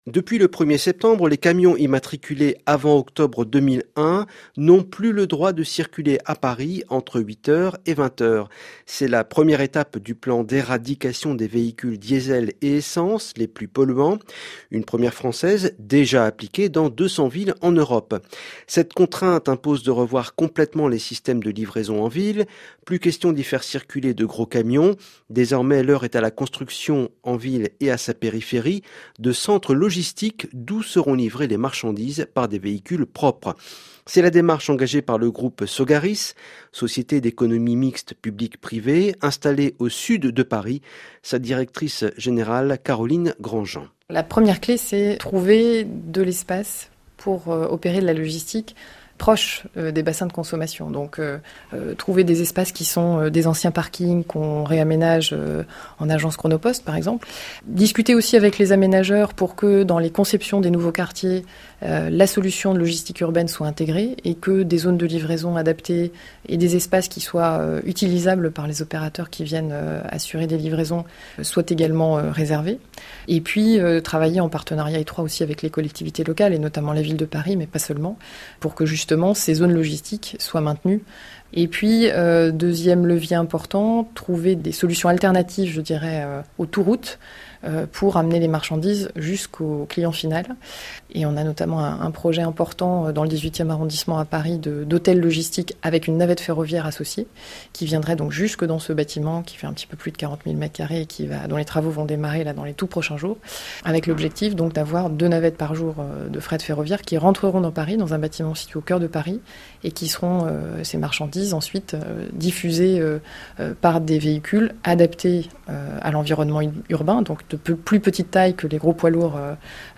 RFI – Chronique Transports du 26 septembre 2015 « Livrer des marchandises sans polluer », de 0’44’’ à 2’09’’